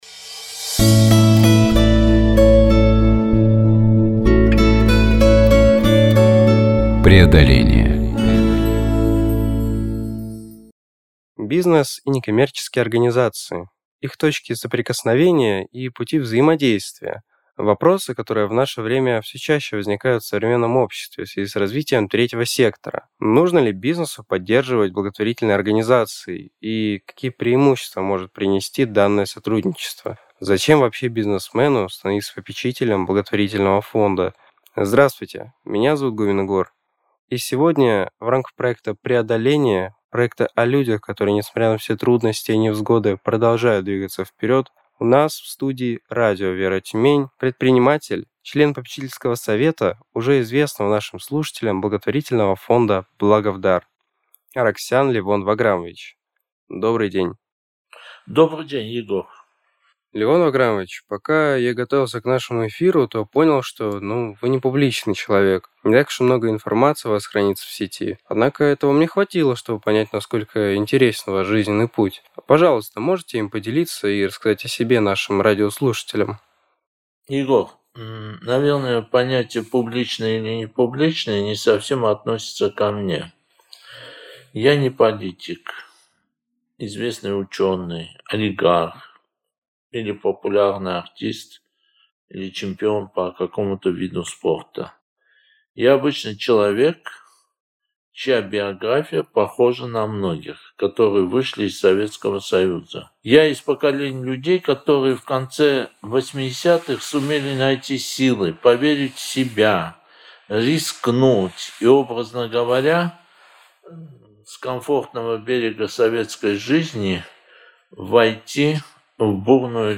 Беседа получилась теплой и содержательной!